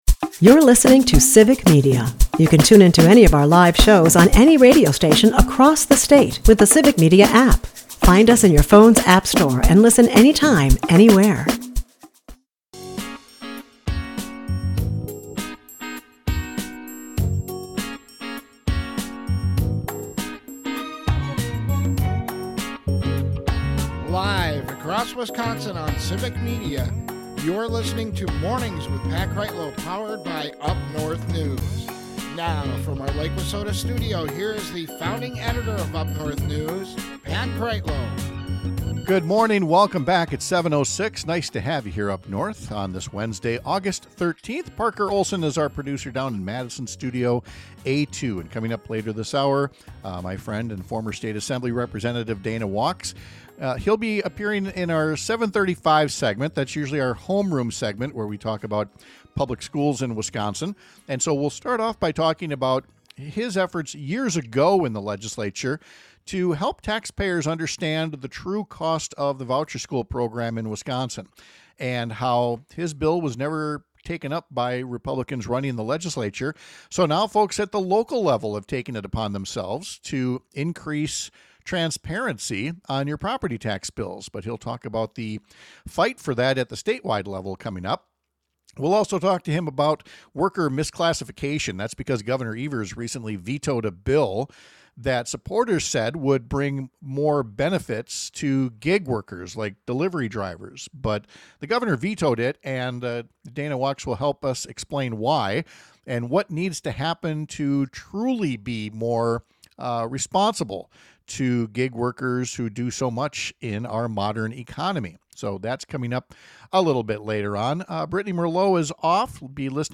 Guests: Dana Wachs
We’ll talk to former state Rep. Dana Wachs about worker misclassification — and also about his early work to bring transparency to property tax bills about the true cost of the voucher schools program.